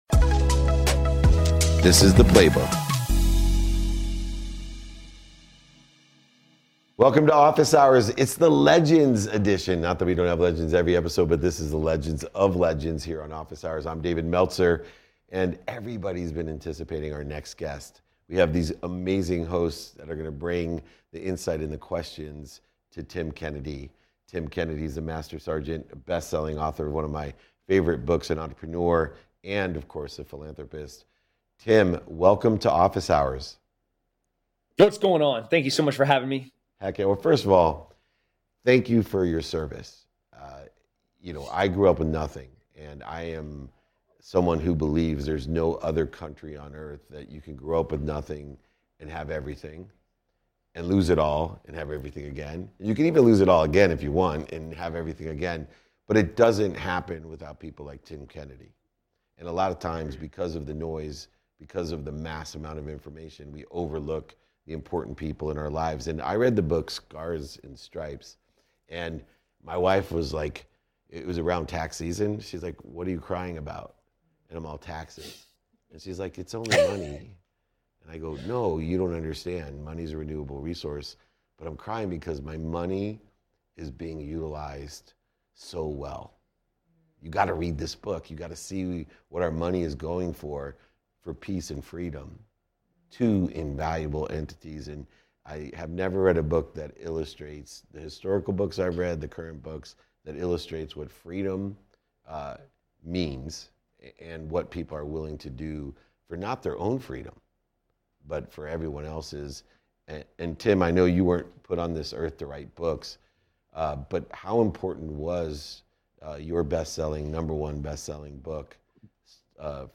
Join us in this episode of Season 3 of Office Hours as we speak with Tim Kennedy, a former Special Forces Operator and best-selling author of "Scars and Stripes." Tim shares with us the profound influence of his book, as well as his perspectives on the crucial qualities that drive success in any profession. He also touches on the role that faith has played in his personal journey, and offers valuable advice on how to challenge others in order to promote their growth and development.